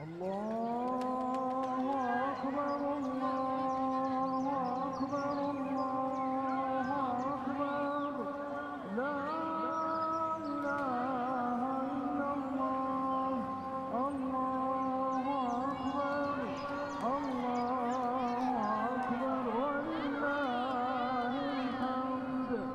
3-Takbeerat.3c32a70d52fd1feb49bf.mp3